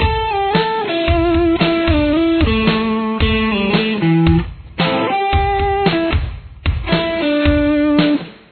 Intro Riff
Guitar 1